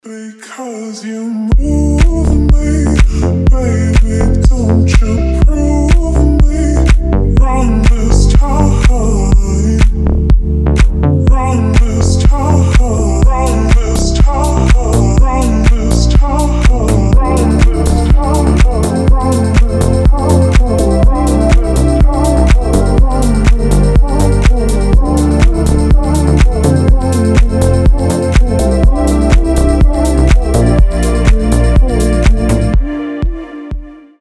• Качество: 320, Stereo
мужской голос
deep house
атмосферные
чувственные
ремиксы